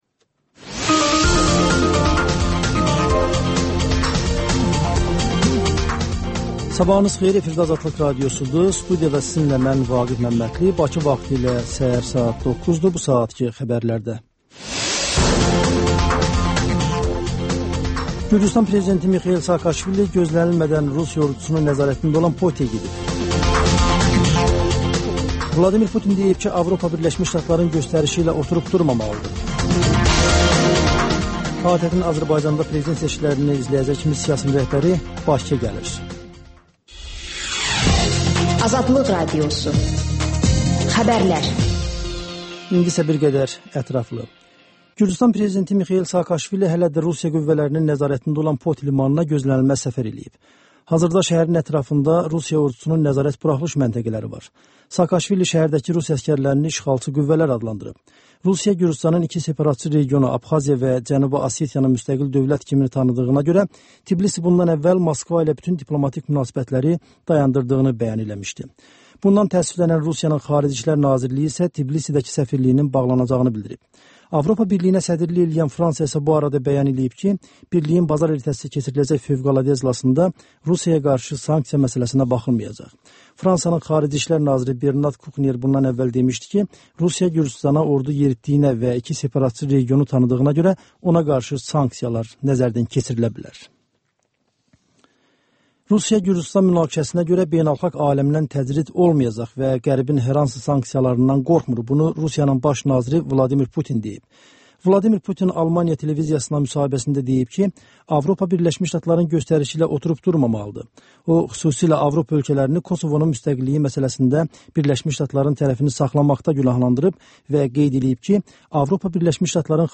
Xəbərlər, XÜSUSİ REPORTAJ: Ölkənin ictimai-siyasi həyatına dair müxbir araşdırmaları və TANINMIŞLAR rubrikası: Ölkənin tanınmış simaları ilə söhbət